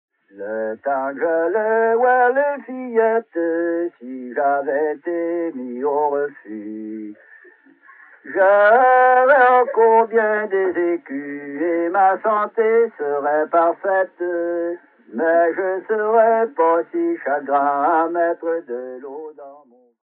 Chanson strophique
Belle-Anse